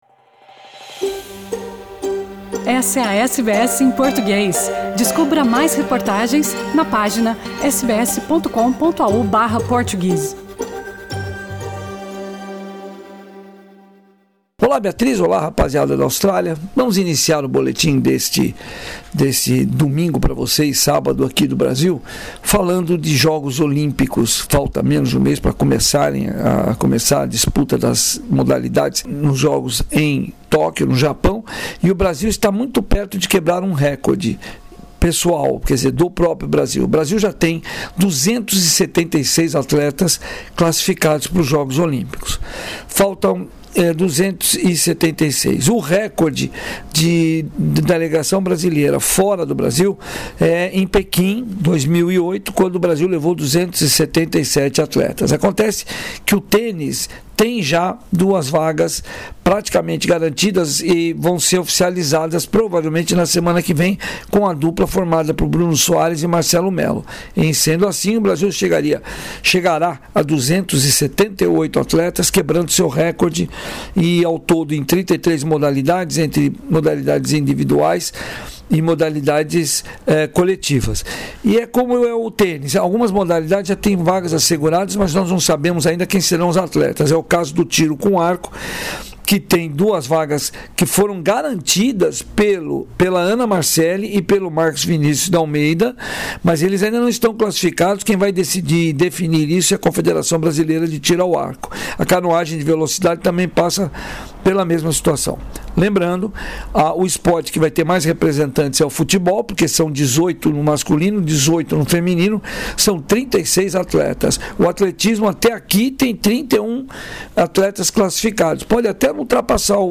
boletim esportivo